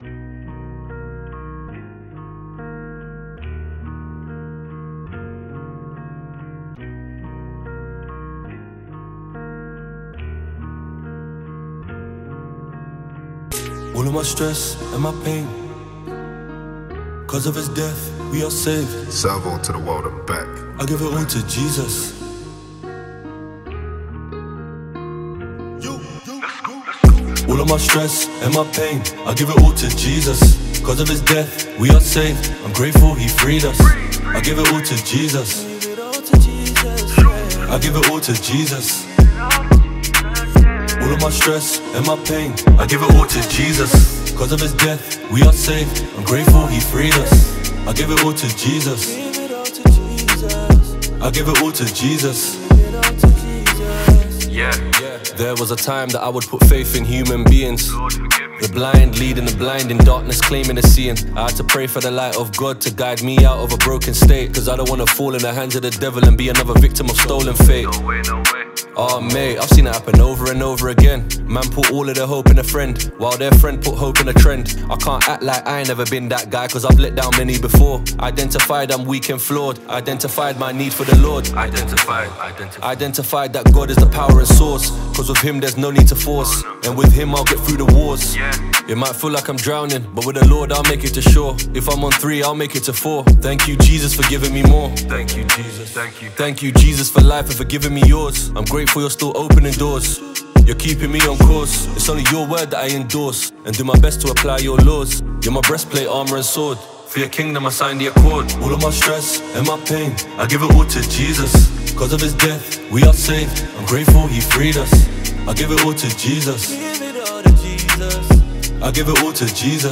GOSPEL MUSIC
Genre: Gospel